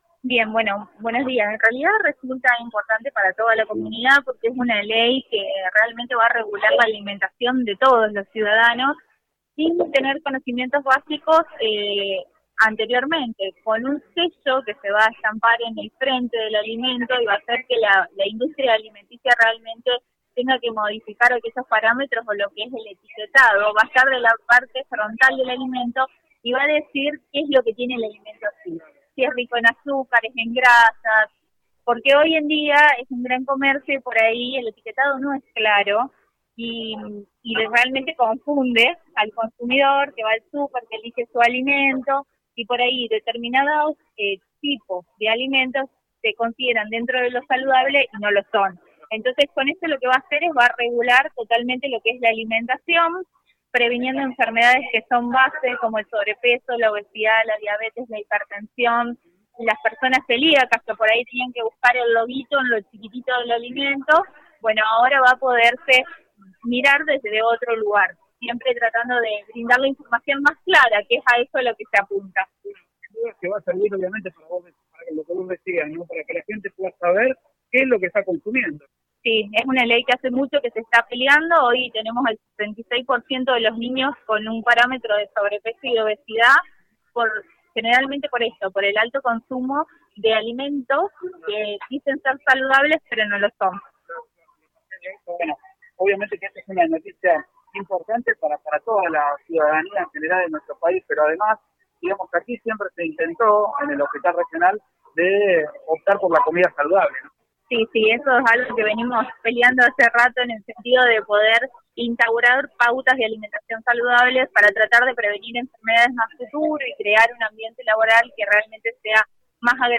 hablaron para Radio Eme Ceres.